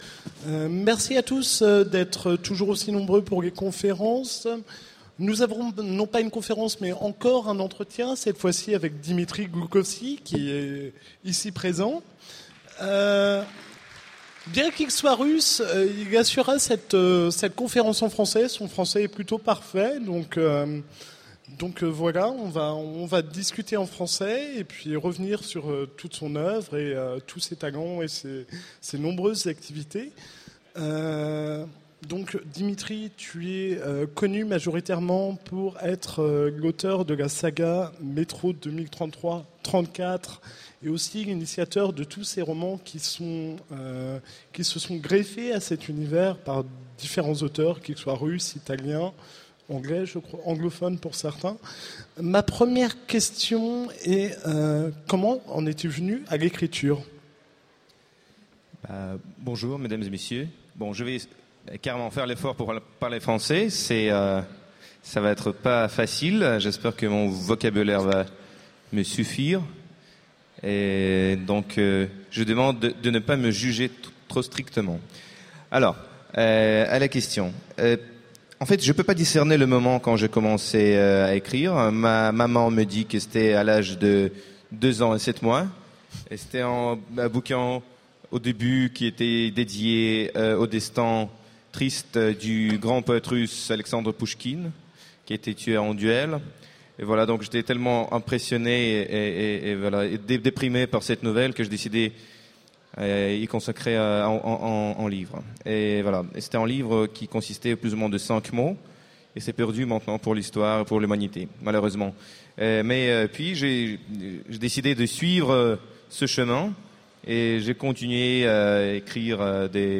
Utopiales 2014 : Rencontre avec Dmitry Glukhovsky
- le 31/10/2017 Partager Commenter Utopiales 2014 : Rencontre avec Dmitry Glukhovsky Télécharger le MP3 à lire aussi Dmitry Glukhovsky Genres / Mots-clés Rencontre avec un auteur Conférence Partager cet article